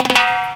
DRUMFILL01-L.wav